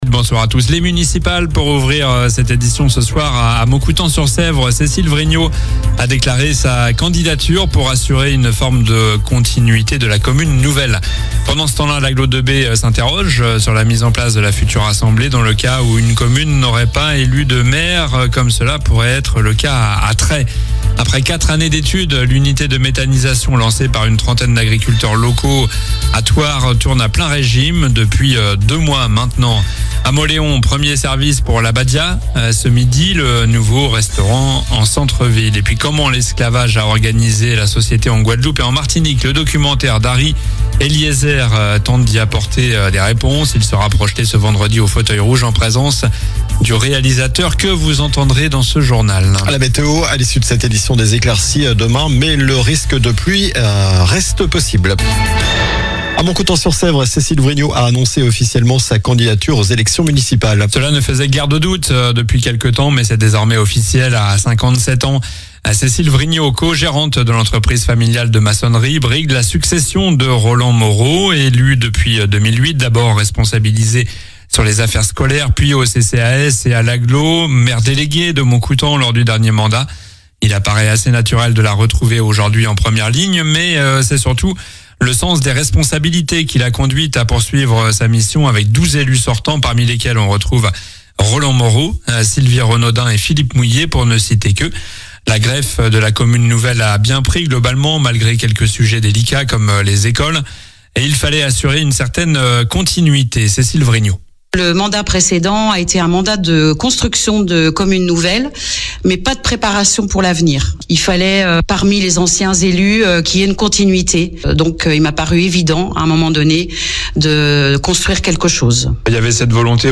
Journal du mercredi 21 janvier (soir)